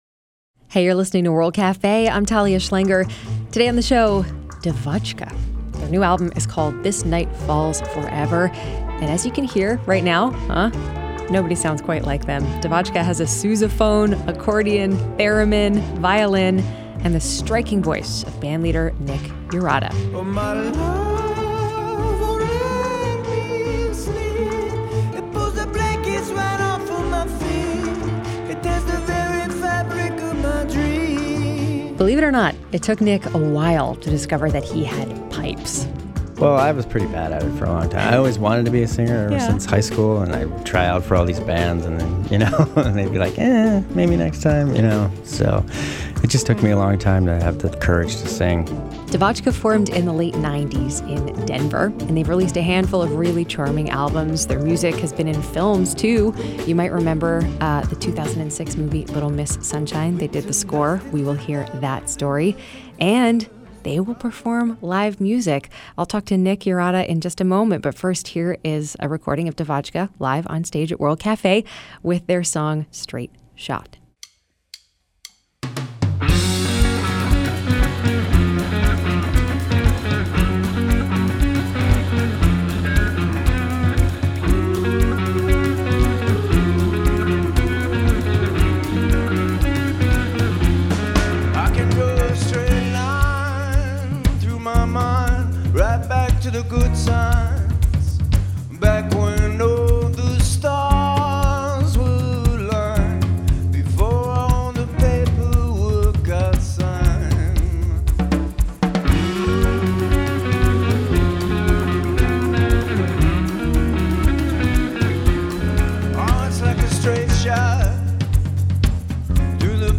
blend the spirit of indie, cabaret and world music
Performs live
Recorded live